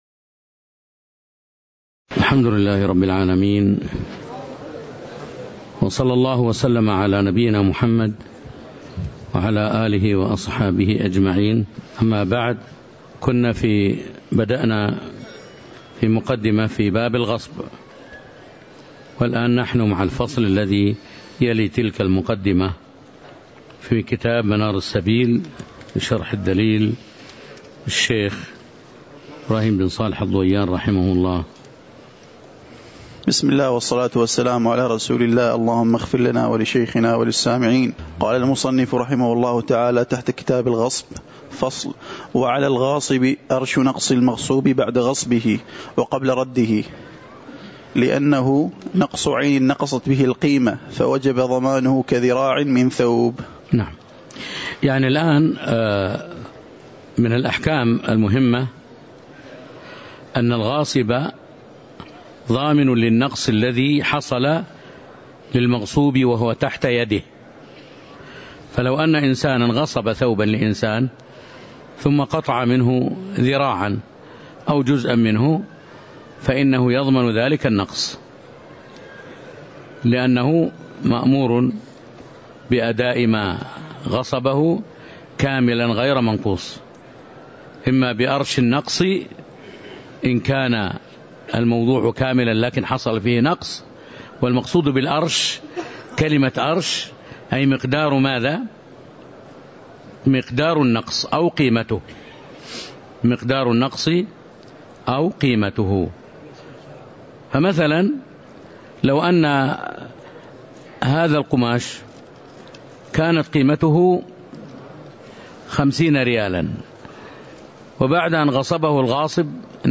تاريخ النشر ٩ شوال ١٤٤٣ هـ المكان: المسجد النبوي الشيخ